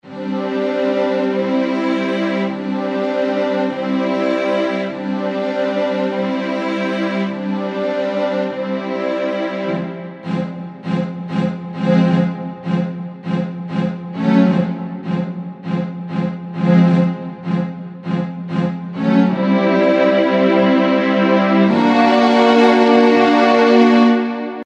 HALion6 : Studio Strings